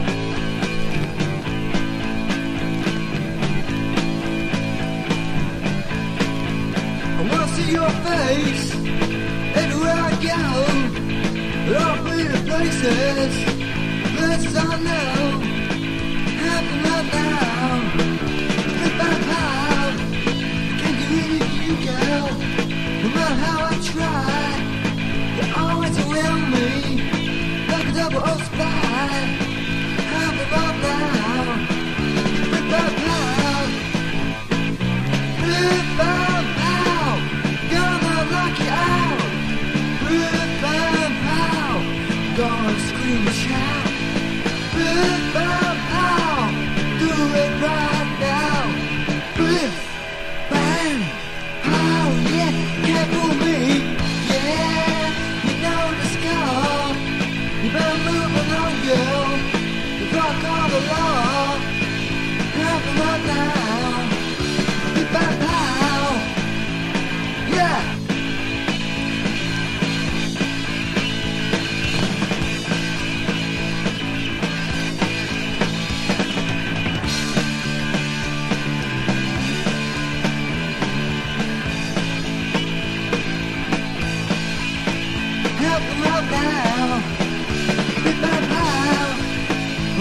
NEO ACOUSTIC / GUITAR POP
POPS# PUNK / HARDCORE